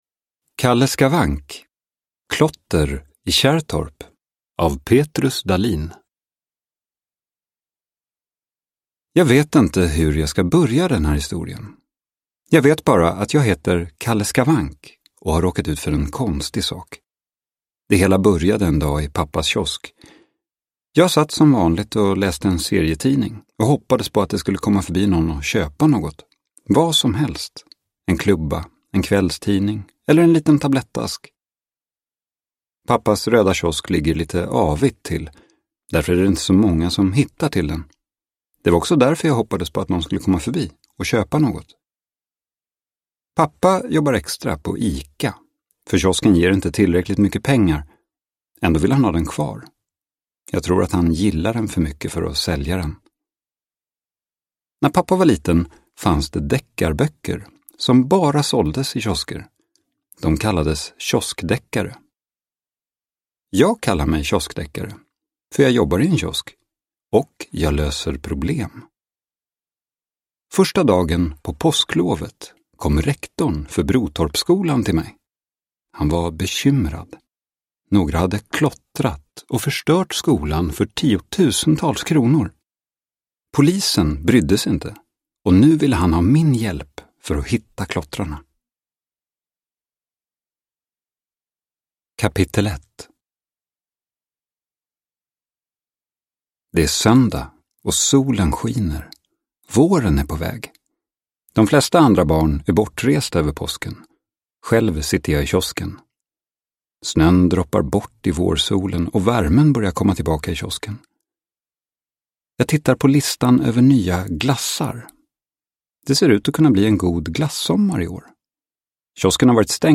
Klotter i Kärrtorp – Ljudbok – Laddas ner